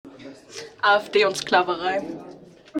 MS Wissenschaft @ Diverse Häfen